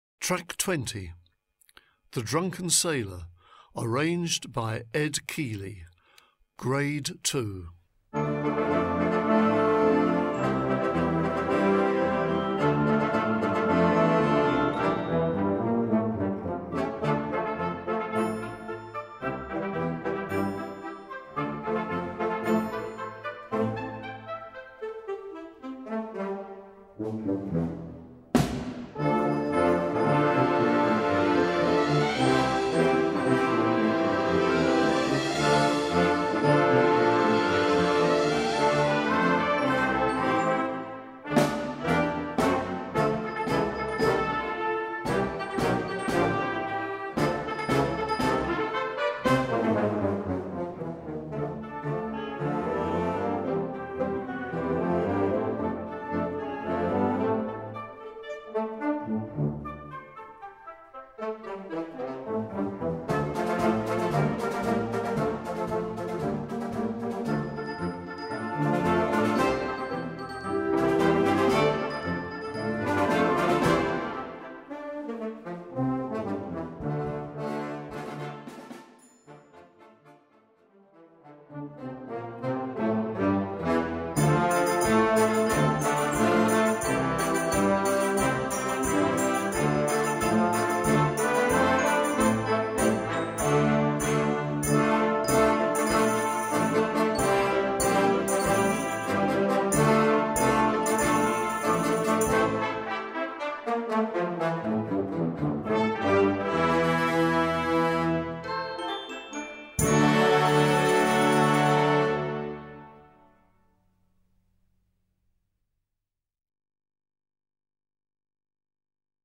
Gattung: Jugendblasorchester
Besetzung: Blasorchester
This familiar jolly ditty never fails to entertain